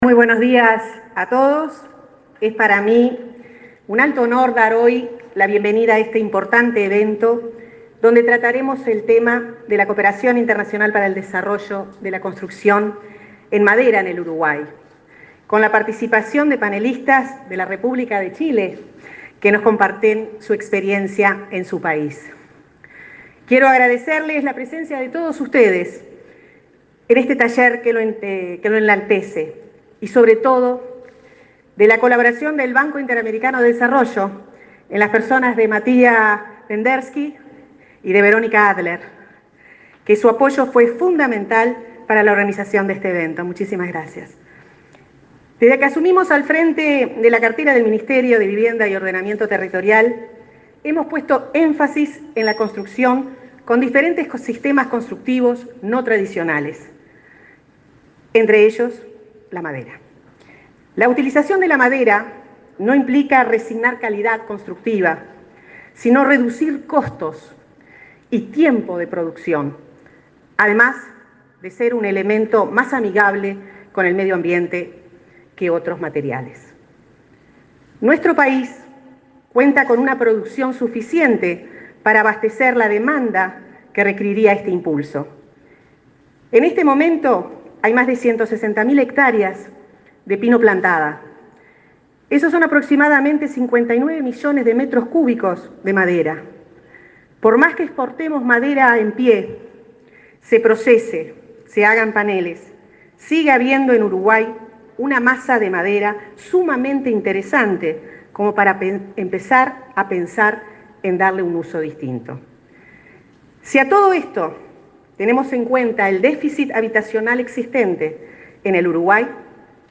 Palabras de la ministra de Vivienda, Irene Moreira
La ministra de Vivienda, Irene Moreira, abrió este miércoles 6 el Taller Internacional de Normativas para la Construcción en Madera, que se realiza en